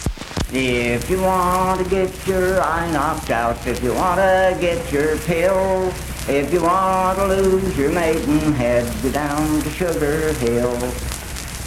Unaccompanied vocal music performance
Dance, Game, and Party Songs, Bawdy Songs
Voice (sung)
Spencer (W. Va.), Roane County (W. Va.)